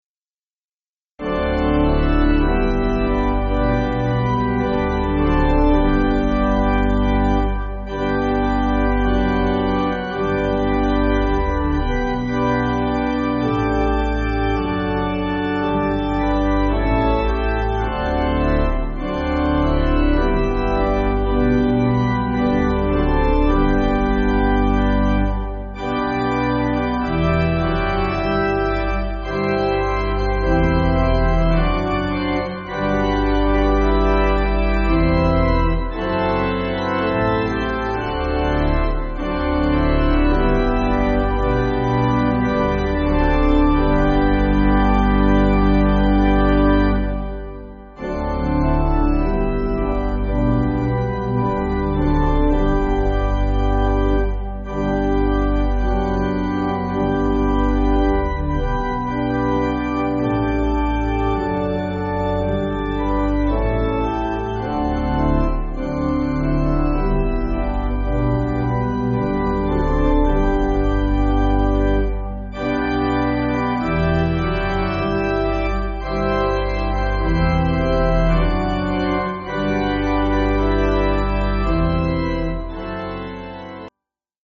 (CM)   3/G